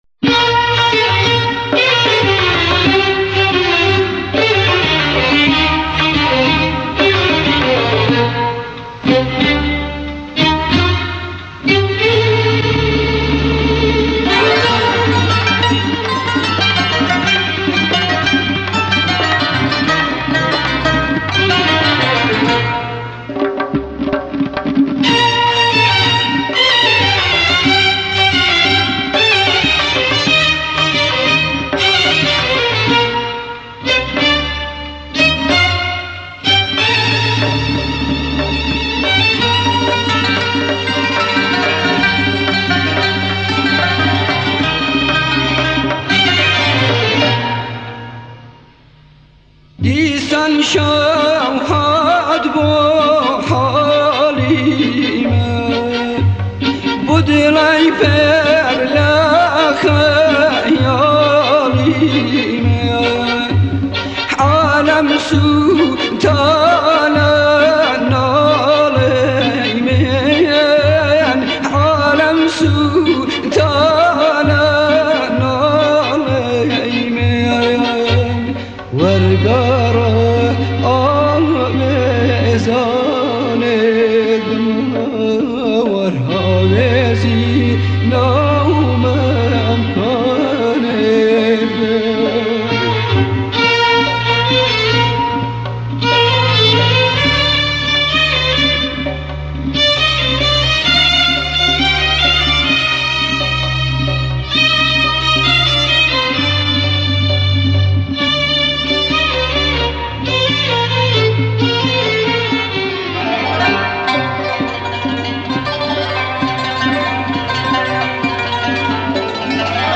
جدیدترین اهنگ های کردی فولکلور